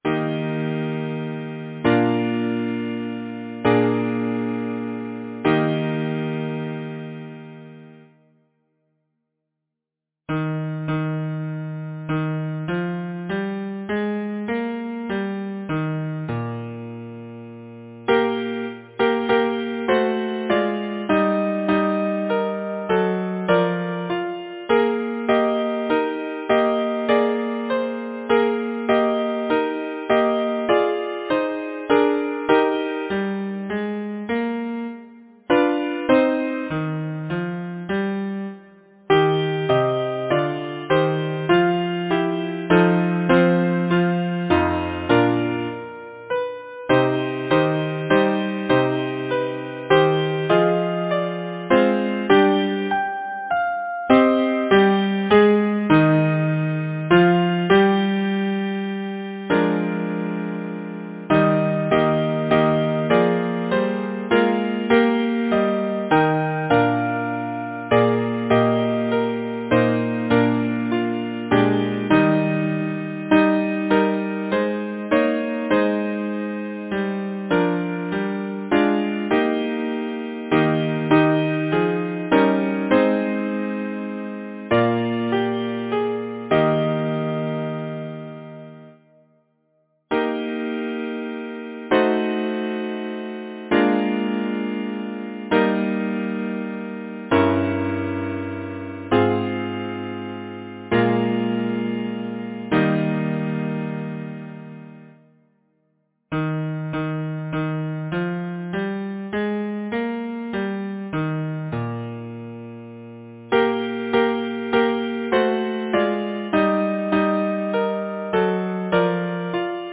Title: Tears Composer: John Liptrot Hatton Lyricist: Beatrice Abercrombie Number of voices: 4vv Voicing: SATB Genre: Sacred, Partsong
Language: English Instruments: A cappella
First published: ca. 1881 Ashdown & Parry Description: Sacred Partsong for Mixed Voices